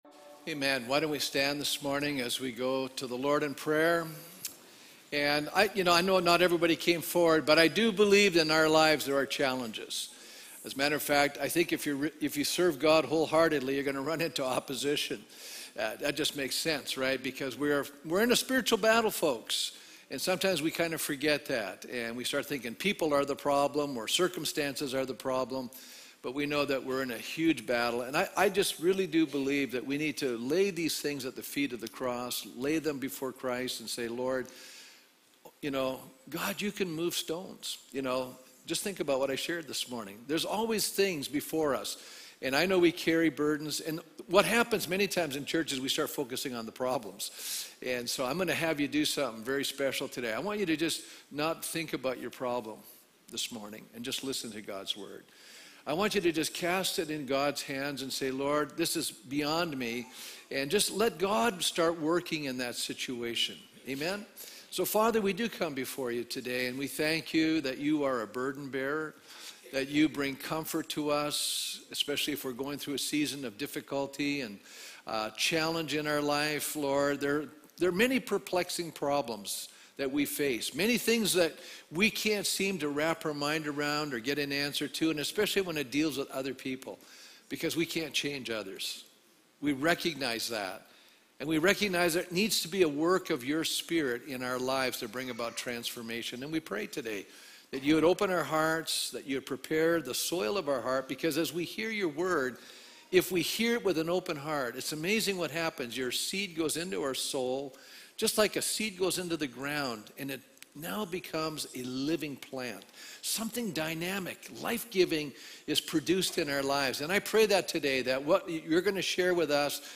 Easter Sunday 1 Corinthians 15:1-11